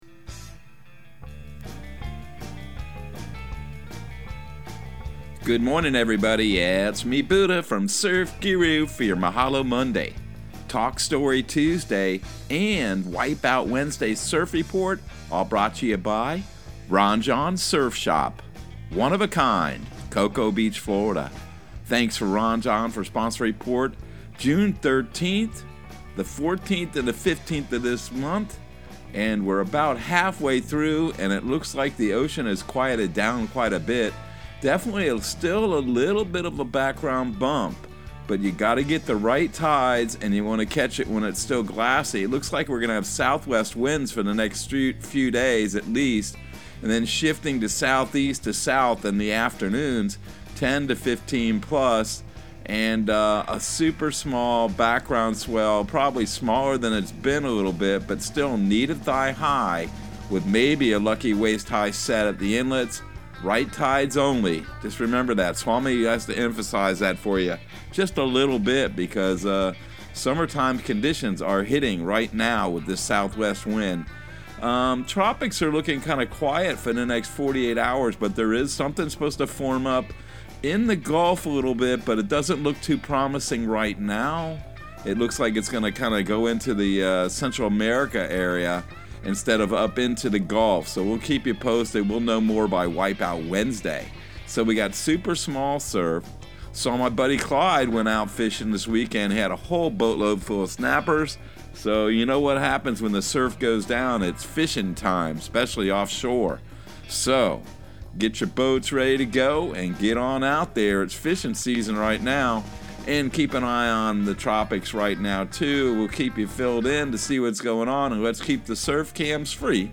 Surf Guru Surf Report and Forecast 06/13/2022 Audio surf report and surf forecast on June 13 for Central Florida and the Southeast.